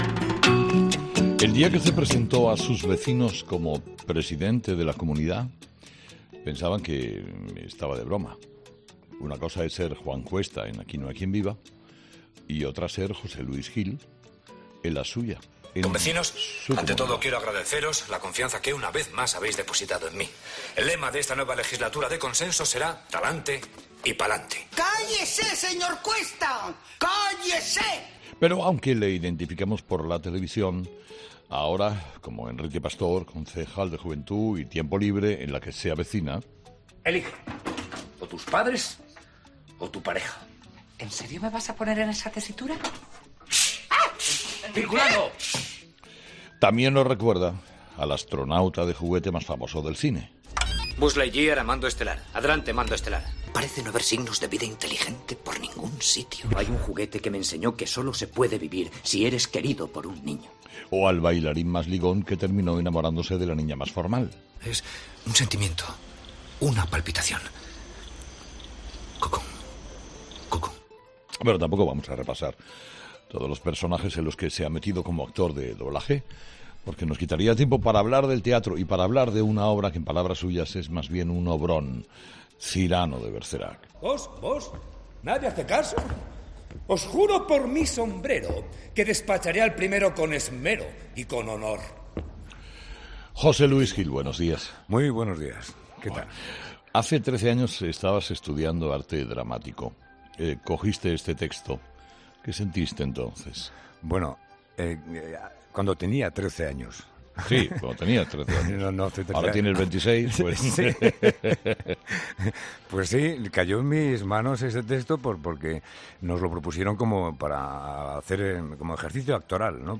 Escucha la entrevista a José Luis Gil en Herrera en COPE